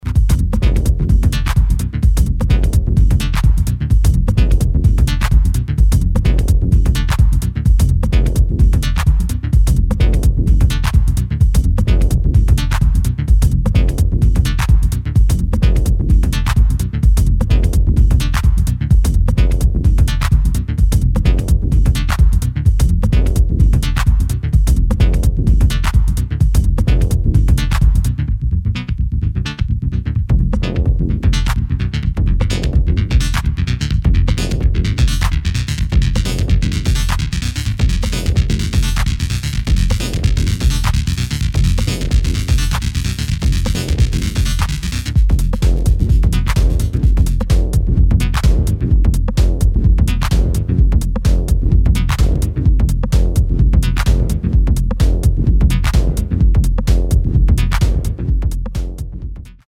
[ DETROIT TECHNO ]